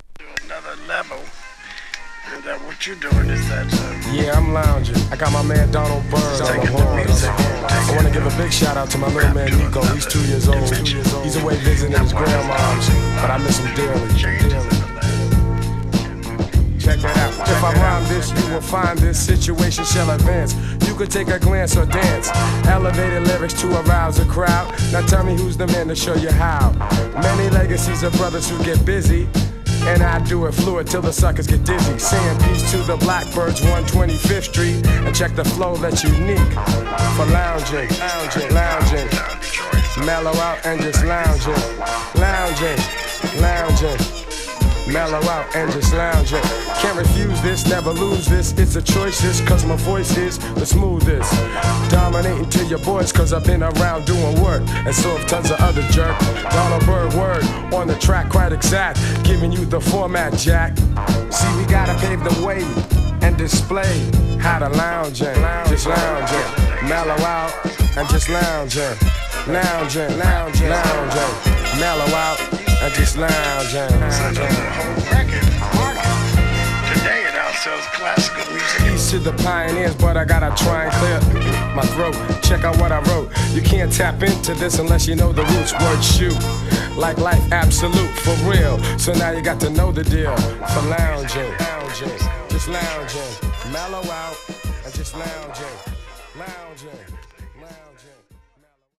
(ALBUM VOCAL)